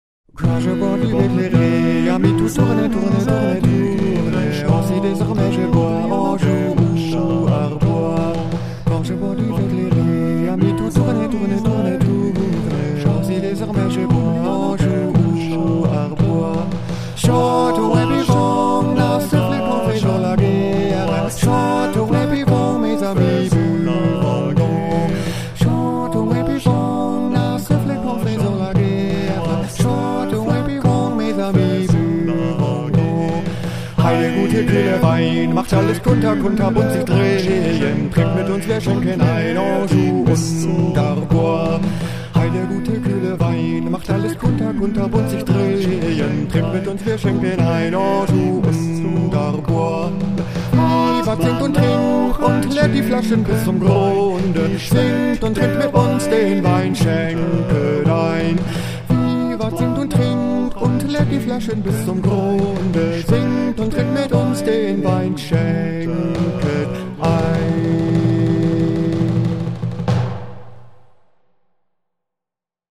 Festliches Weihnachtsspecial im Trio
Die Grooving Harmonists, Vocaltrio und Jazzband in einem, spielen swingende Weihnachtslieder und Songs für die kalte Jahreszeit.
stimmungsvoll – harmonischen Gesängen aus der Zeit der Renaissance.